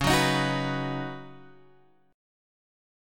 C# 9th Suspended 4th